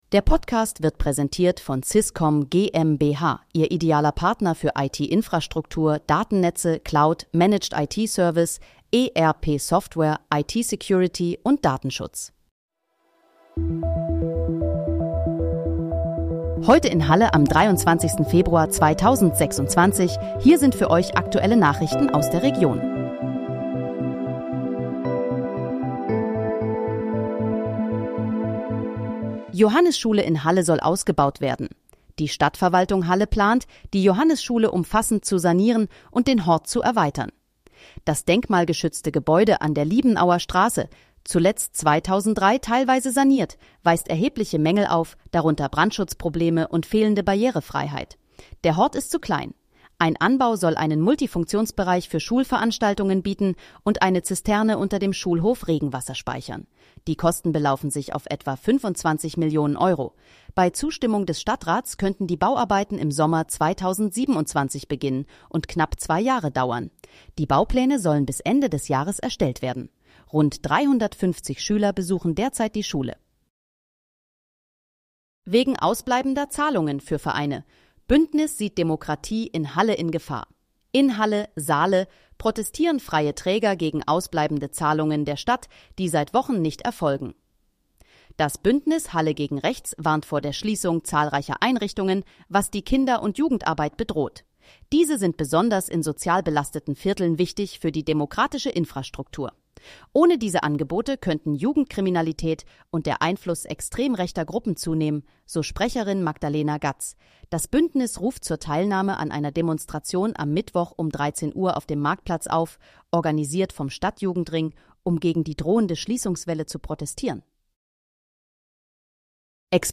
Heute in, Halle: Aktuelle Nachrichten vom 23.02.2026, erstellt mit KI-Unterstützung
Nachrichten